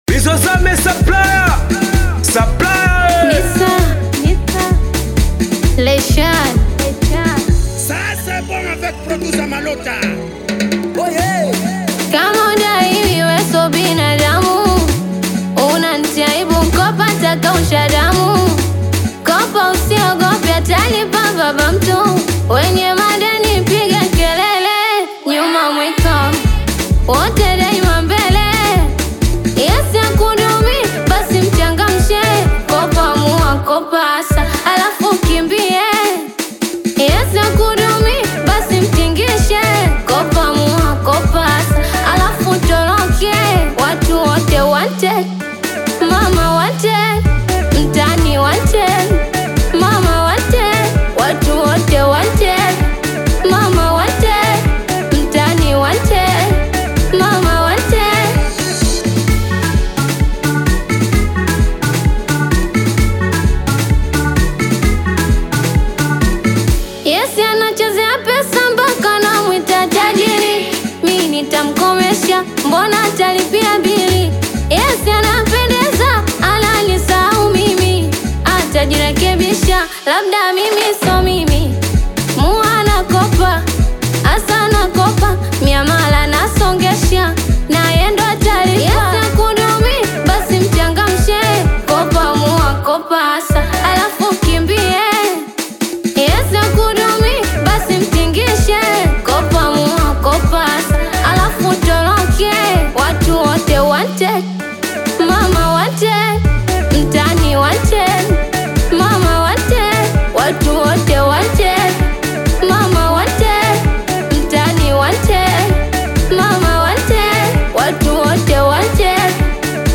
Singeli